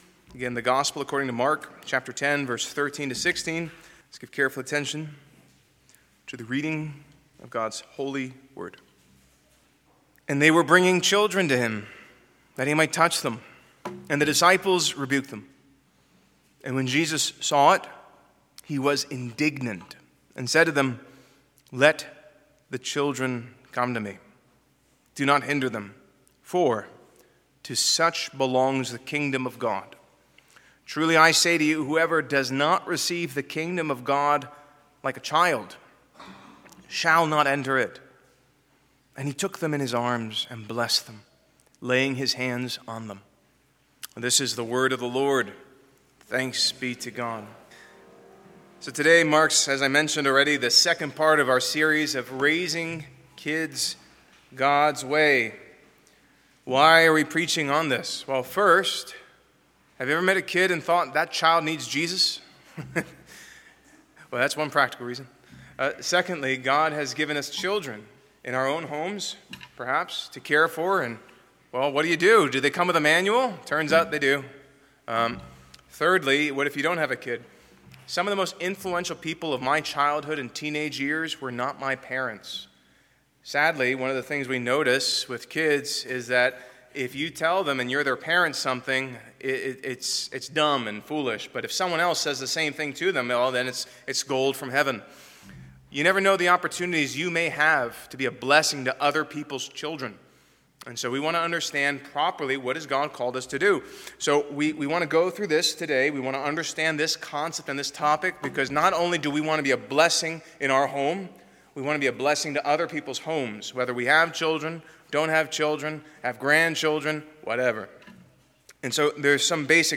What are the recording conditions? Passage: Mark 10:13-16 Service Type: Morning Worship